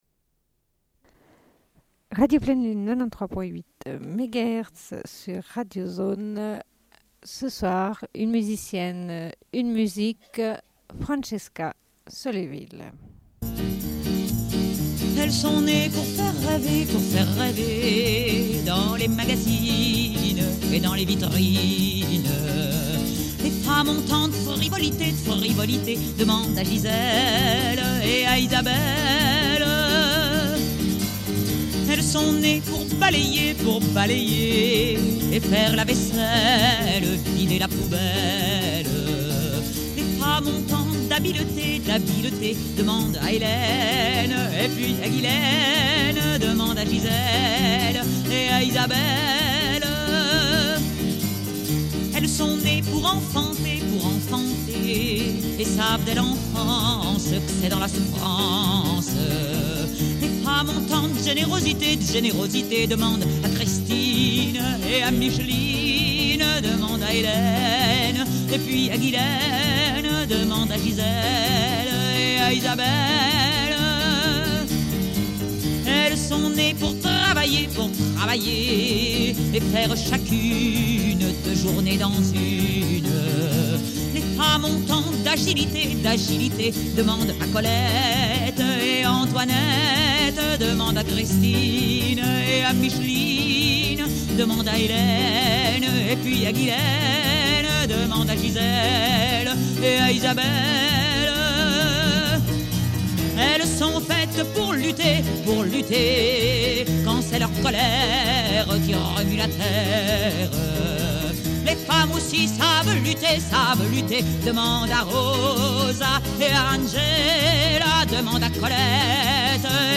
Une cassette audio, face A31:29
Émission Une musicienne, une musique consacrée à Francesca Solleville. Diffusion d'un entretien avec l'artiste.